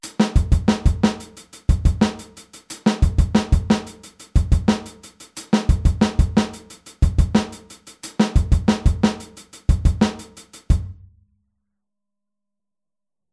Aufteilung linke und rechte Hand auf HiHat und Snare
Groove20-16tel.mp3